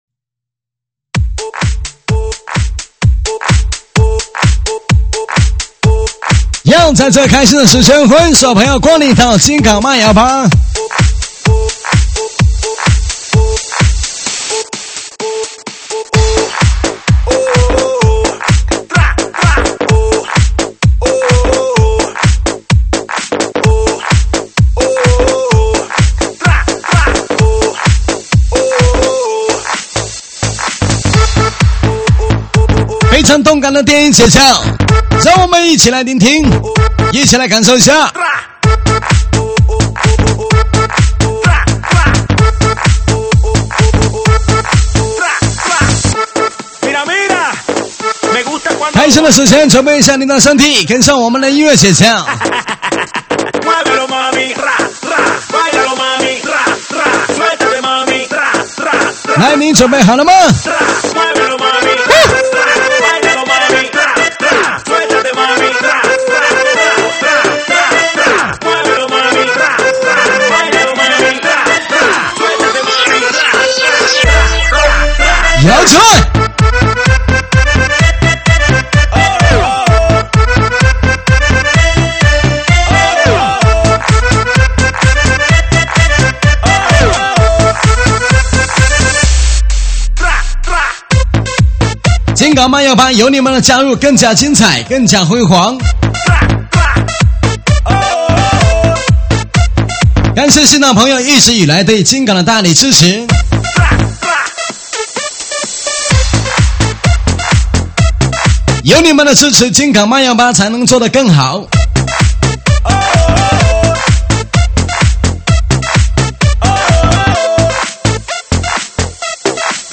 舞曲类别：喊麦现场